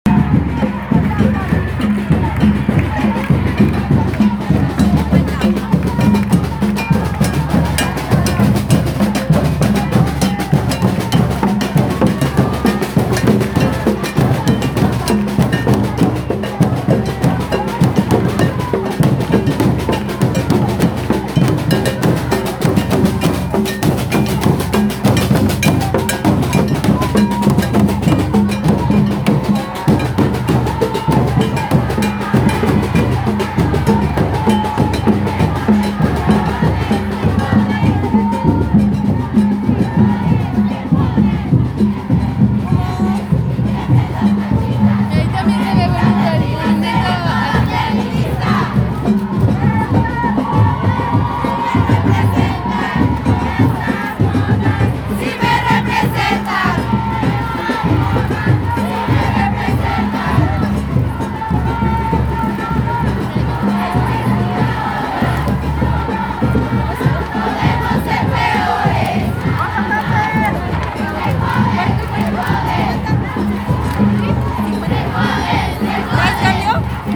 Gendered street demonstrations